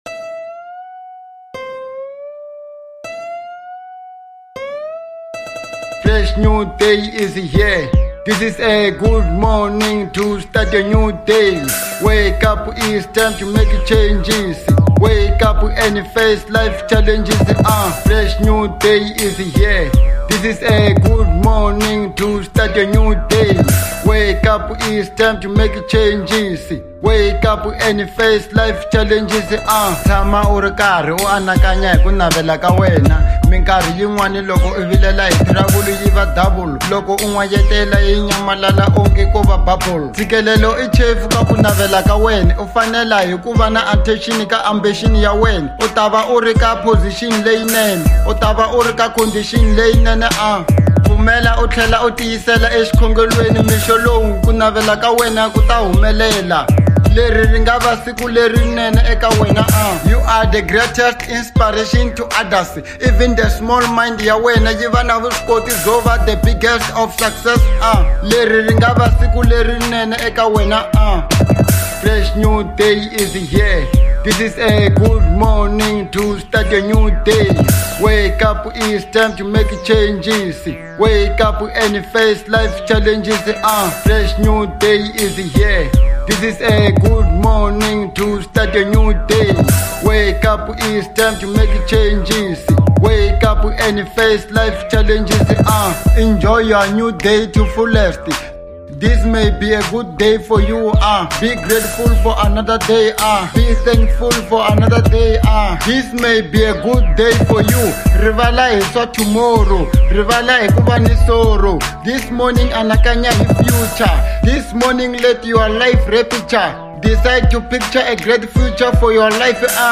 02:57 Genre : Hip Hop Size